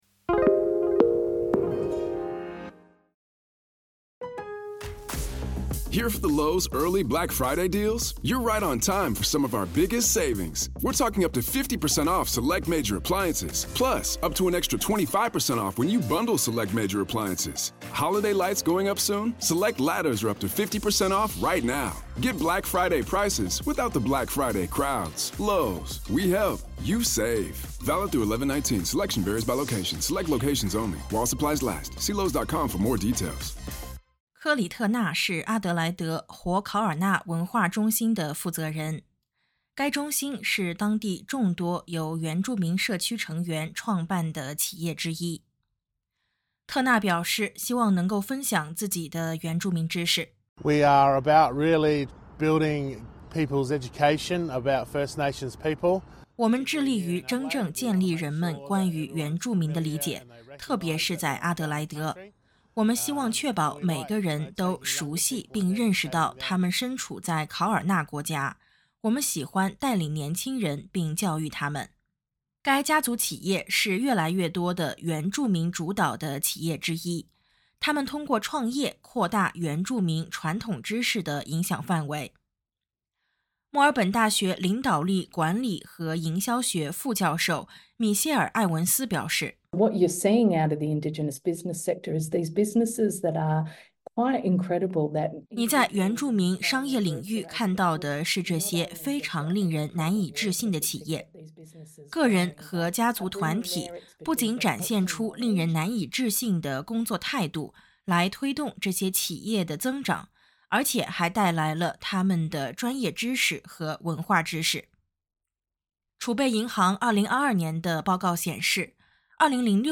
一份新的快照显示，原住民主导的企业和贸易商正在对国家经济产生越来越大的影响。它们日益增长的影响不仅体现在金钱价值上，还体现在为原住民提供更多的机会和自决权上。点击音频，收听完整报道。